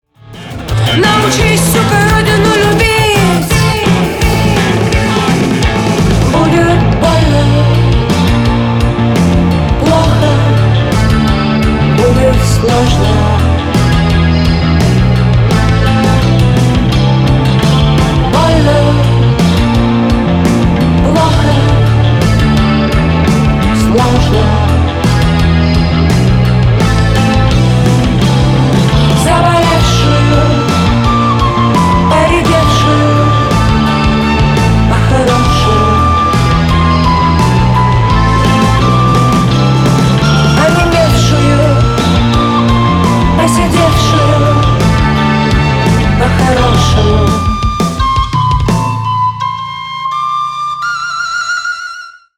• Качество: 320, Stereo
женский вокал
грустные